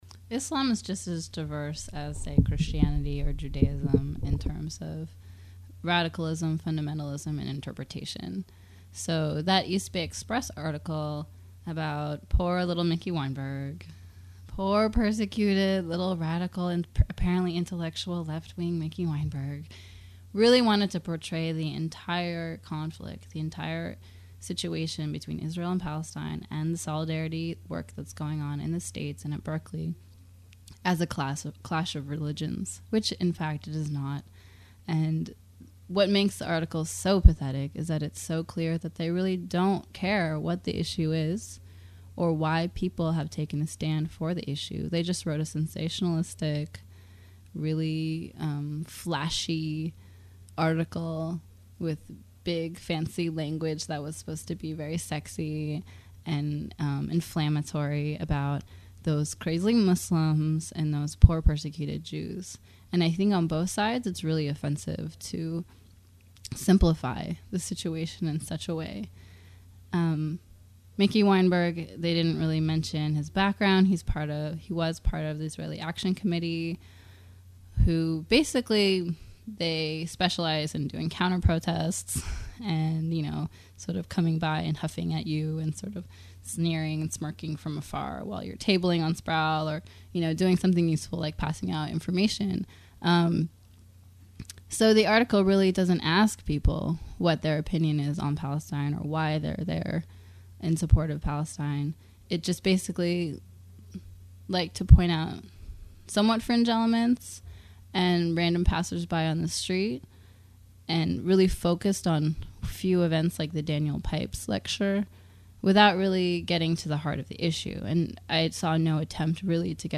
Palestine Interview